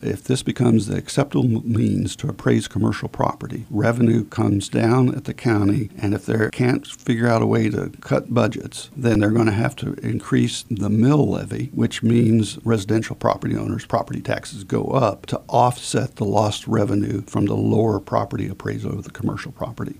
Tom Phillips, a Kansas House of Representative member representing the 67th district, appeared on today’s episode of In Focus to preview some of what he will be working on in the upcoming legislative session in the areas of healthcare, higher education and “dark store theory.”